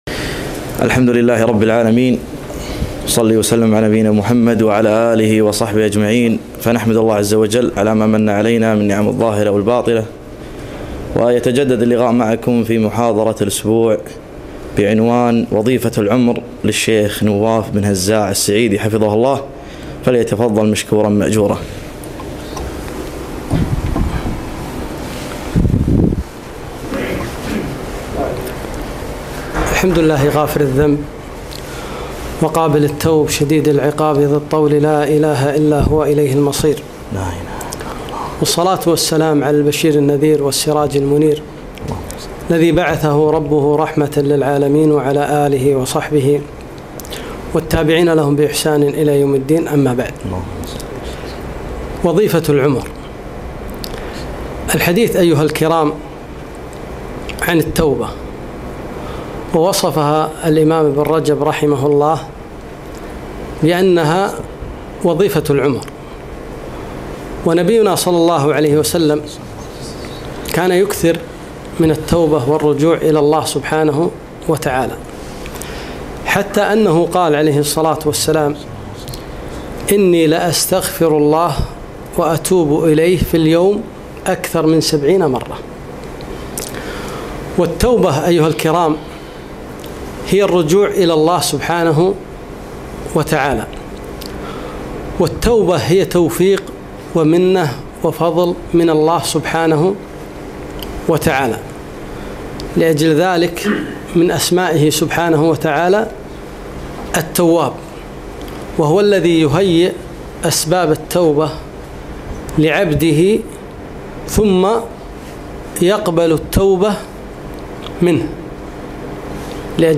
محاضرة - وظيفة العمر